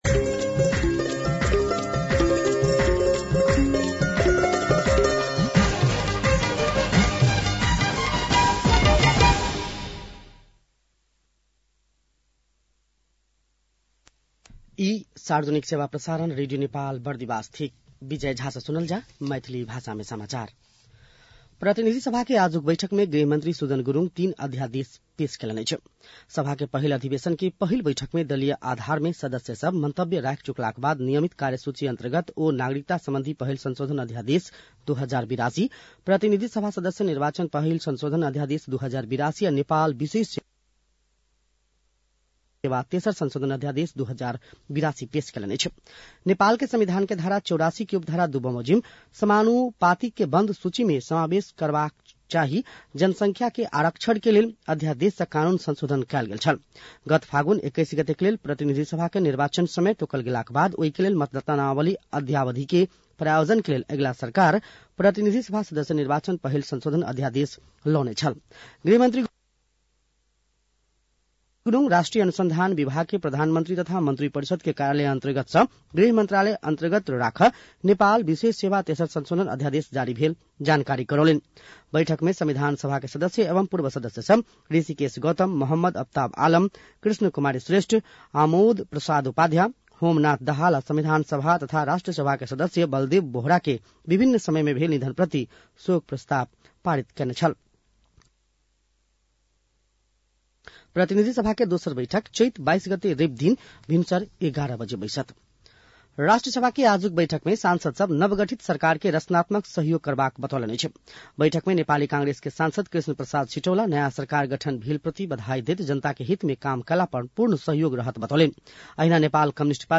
मैथिली भाषामा समाचार : १९ चैत , २०८२
6-pm-maithali-news-.mp3